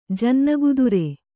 ♪ jannagudure